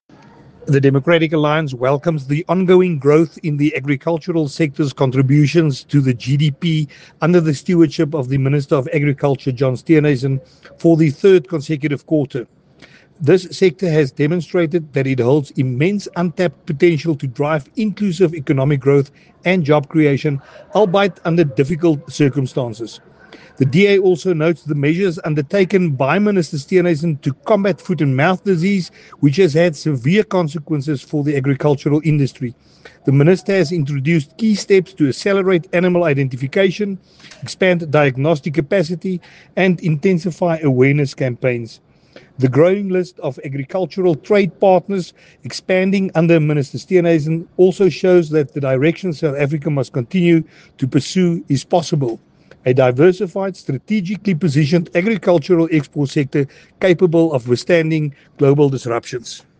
Afrikaans soundbites by Willie Aucamp MP.